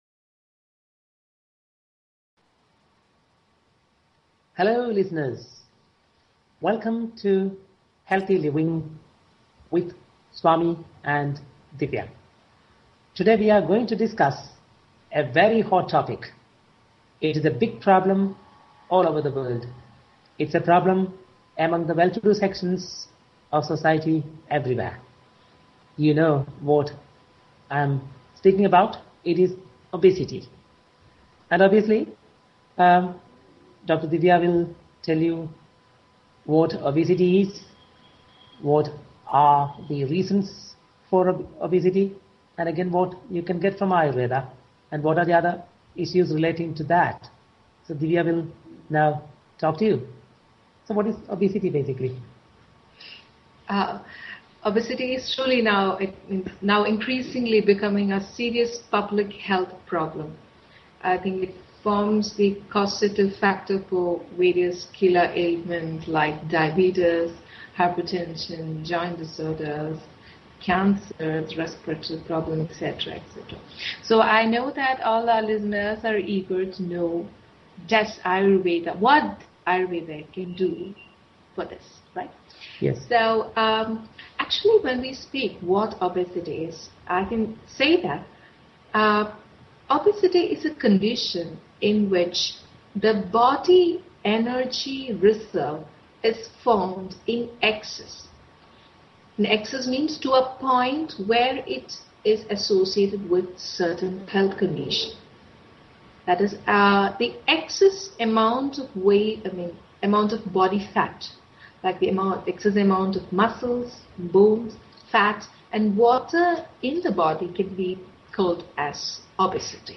Talk Show Episode, Audio Podcast, Healthy_Living and Courtesy of BBS Radio on , show guests , about , categorized as